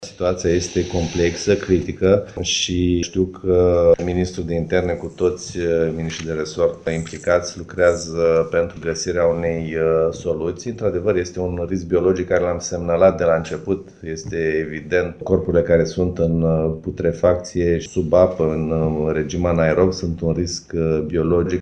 Pe de altă parte, ministrului Sănătăţii, Victor Costache, a declarat ieri că în Portul Midia există un risc biologic mare din cauza cadavrelor intrate în putrefacţie: